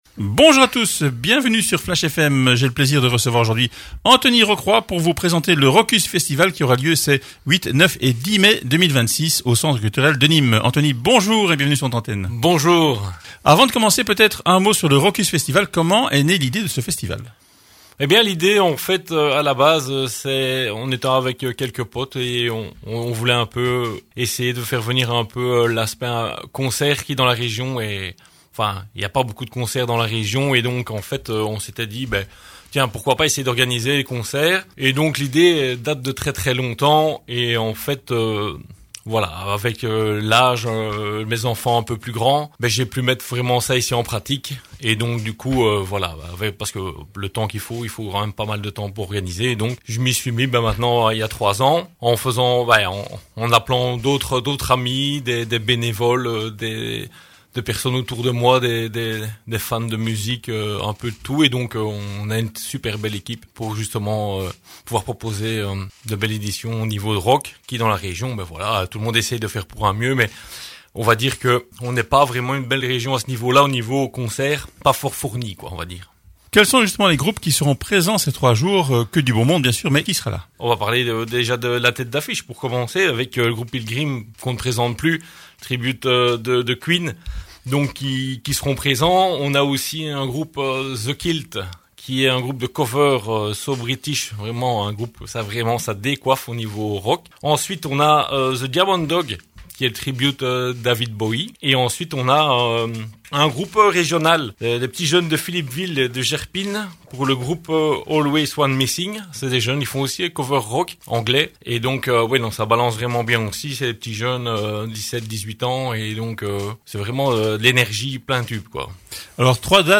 au micro